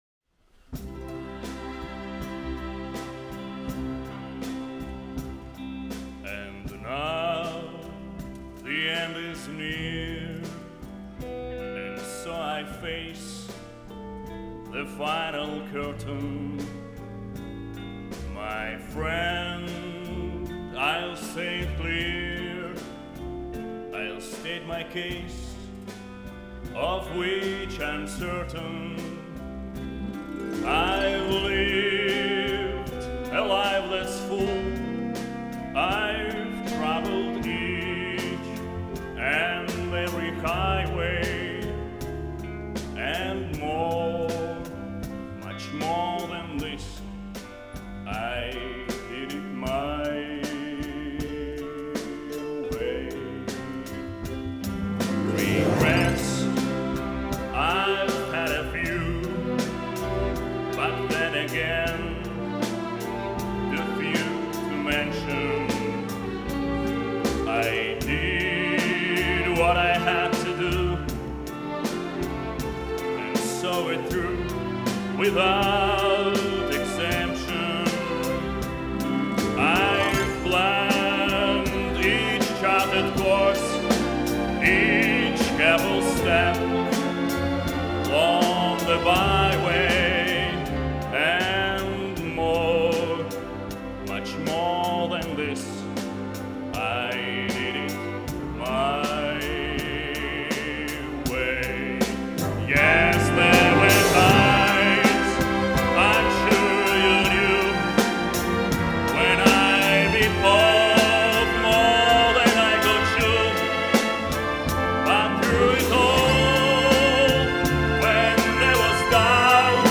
Populārā mūzika
Dziesmas
Lēns
Lirisks
Siguldas estrāde